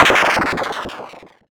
boom_a.wav